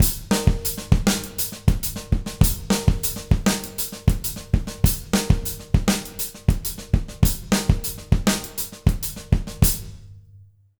100SONGO02-L.wav